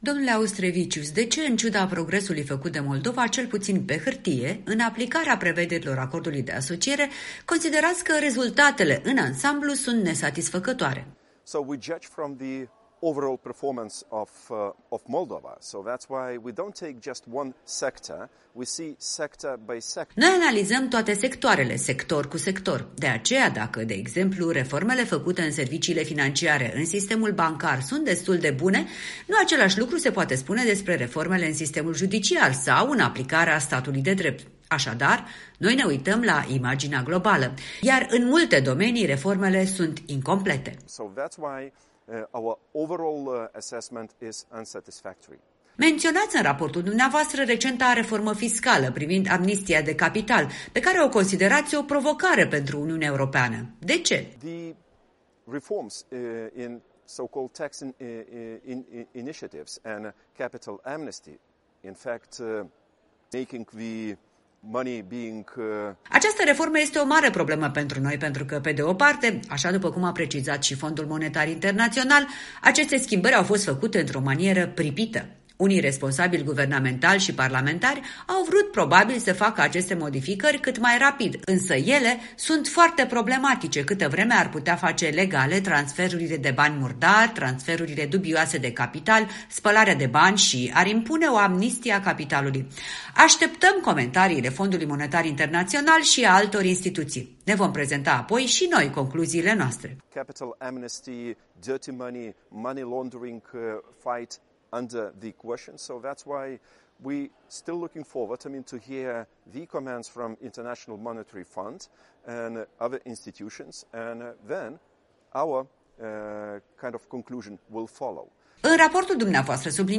Un interviu în exclusivitate cu autorul celui mai recent raport privind aplicarea Acordului de Asociere al R.Moldova cu UE.